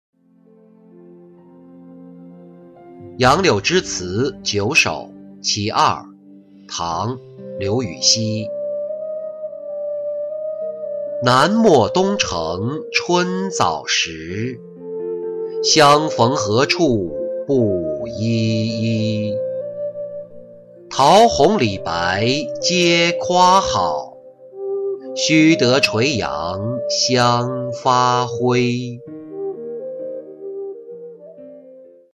杨柳枝词九首·其二-音频朗读